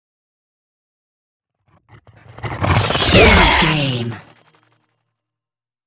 Whoosh metal sword
Sound Effects
dark
intense
whoosh